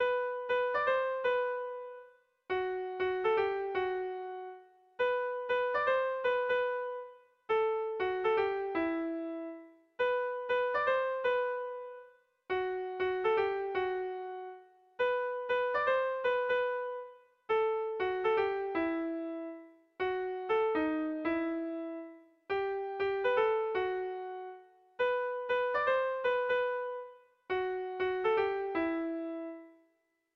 Melodías de bertsos - Ver ficha   Más información sobre esta sección
Irrizkoa
AABA